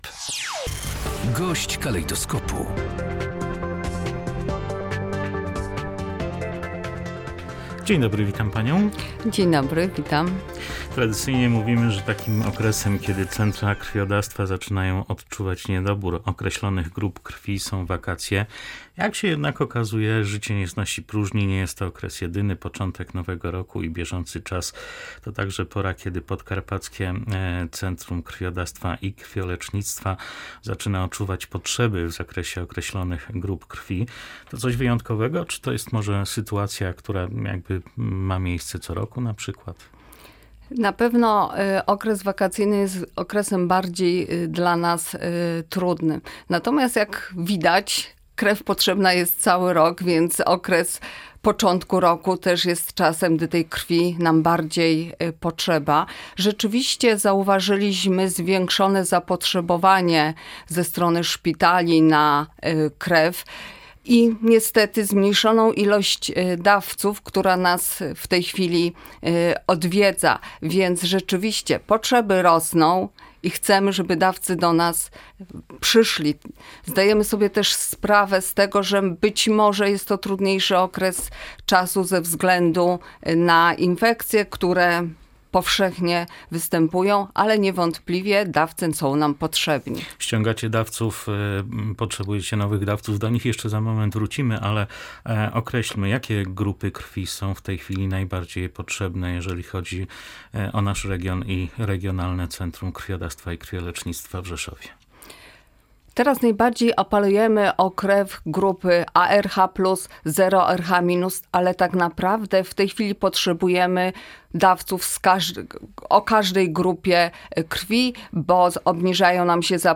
gdy tej krwi bardziej potrzebujemy • Gość dnia • Polskie Radio Rzeszów